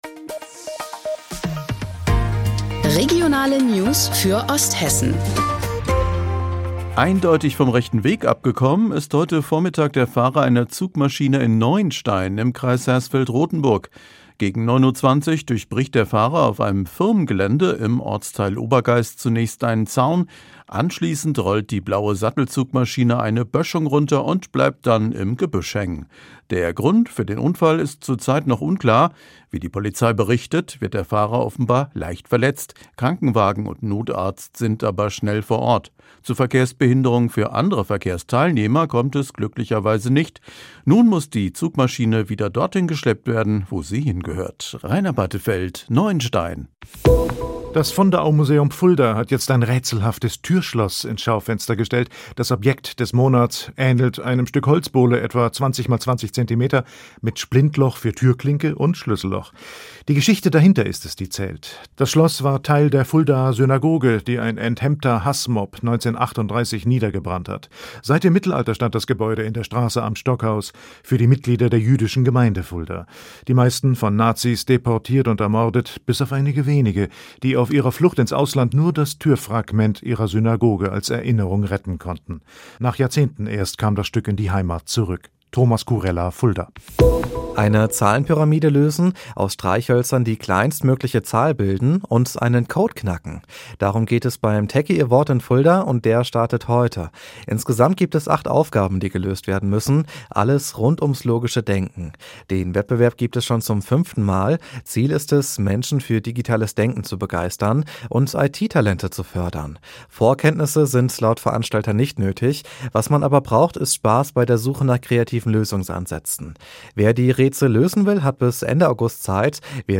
Mittags eine aktuelle Reportage des Studios Fulda für die Region